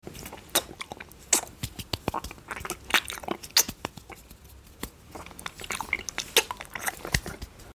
ASMR Lollipop Eating & Clapping